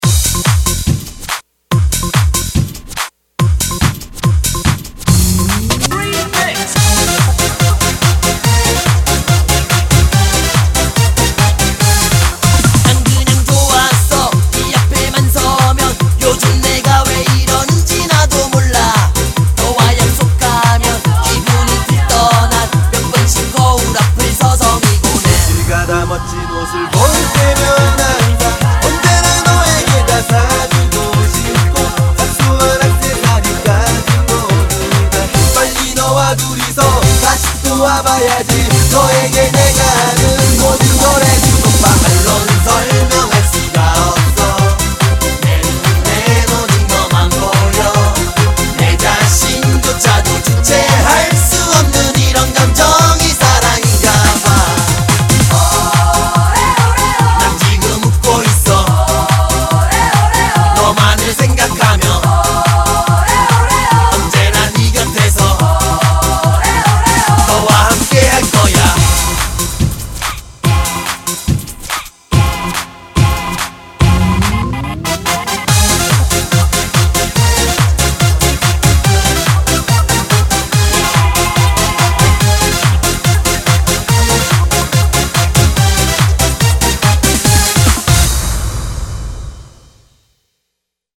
BPM142--1
Audio QualityPerfect (High Quality)